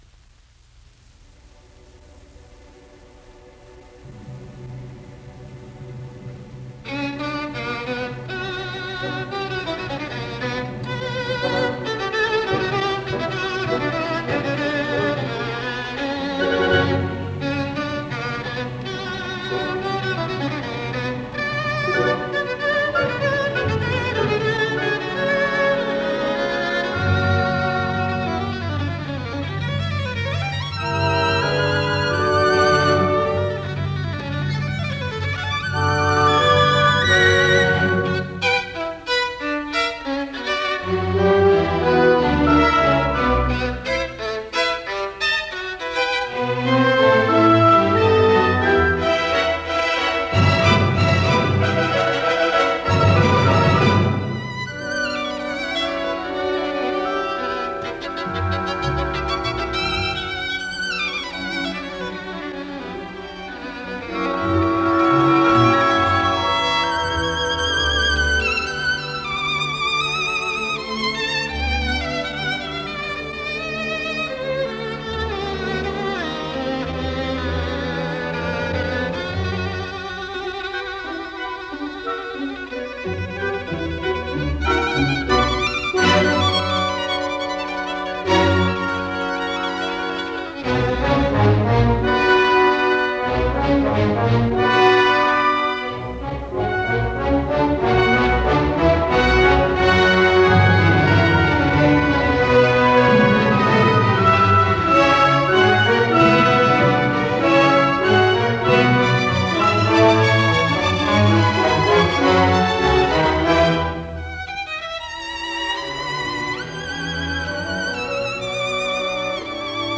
라단조, 2/2박자, 소나타 형식이다. 현악기의 트레몰로 위에 독주 바이올린이 제1주제를 연주하며 시작한다. 제2주제는 마장조로, 독주 바이올린이 노래한다. 재현부에서는 제1주제가 생략되고, 규모가 큰 코다가 악장을 마무리한다.